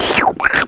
SLURP5.WAV